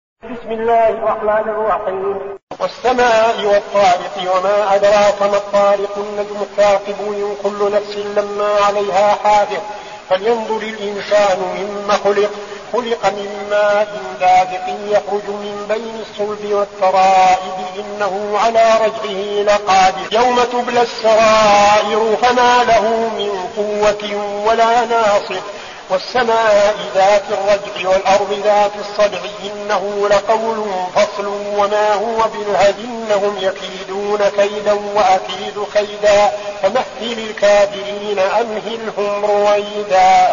المكان: المسجد النبوي الشيخ: فضيلة الشيخ عبدالعزيز بن صالح فضيلة الشيخ عبدالعزيز بن صالح الطارق